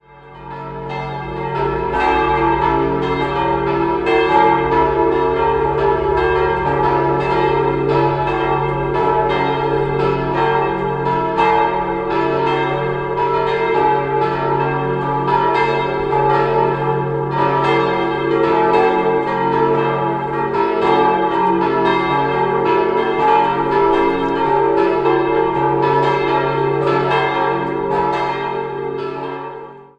4-stimmiges Geläut: d'-e'-g'-b' Die Glocken 1 und 3 wurden 1947 von Petit&Edelbrock in Gescher gegossen, die zweitgrößte schuf 1698 Gordian Schelchshorn in Regensburg und die vierte entstand 1934 bei Gugg in Straubing.